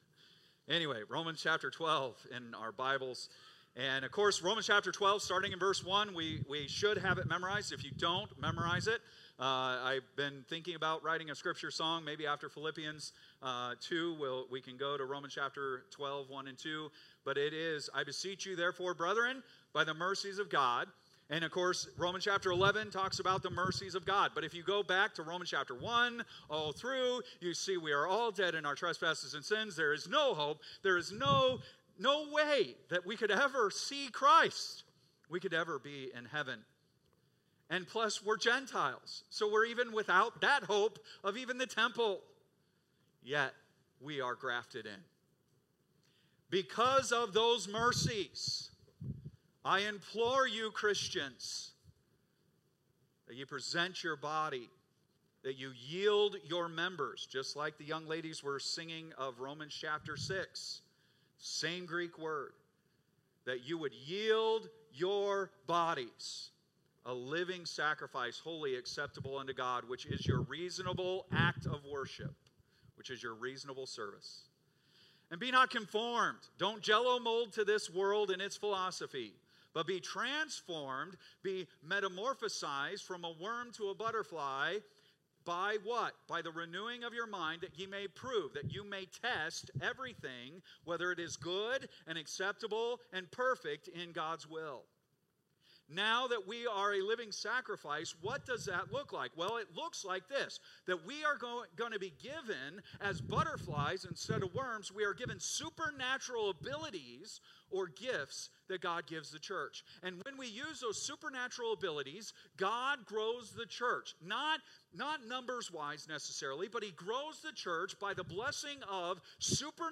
Date: July 13, 2025 (Sunday Morning)